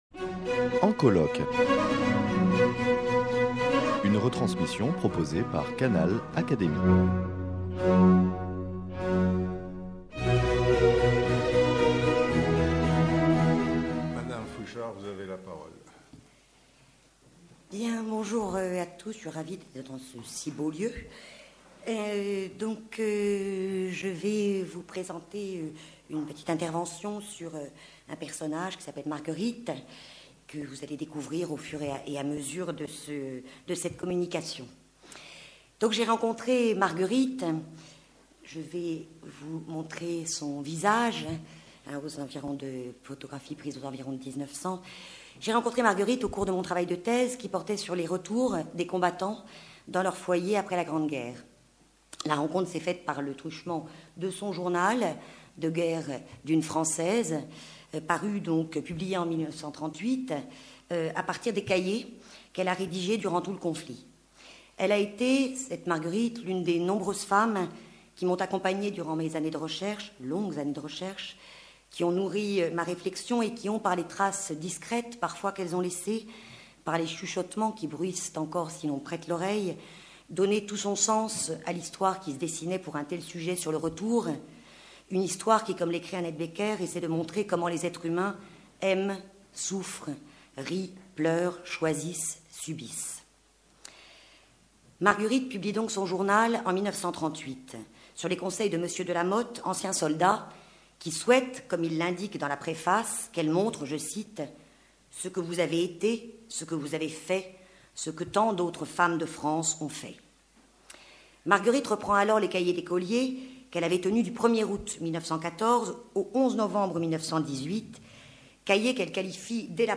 prononcée le 13 janvier 2016 lors des journées d’étude « La guerre et les femmes »